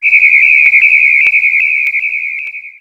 ihob/Assets/Extensions/RetroGamesSoundFX/UFO/UFO09.wav at master
UFO09.wav